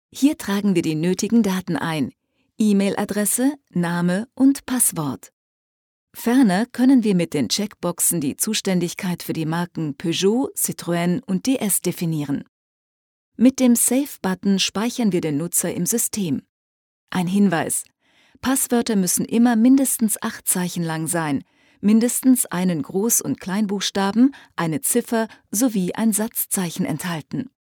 Ausgebildete Sprecherin und Schauspielerin mit variabler warmer Stimme, bekannt aus Radio- und TV.
deutsche Sprecherin, German VoiceTalent.
Sprechprobe: eLearning (Muttersprache):
german female voice over talent.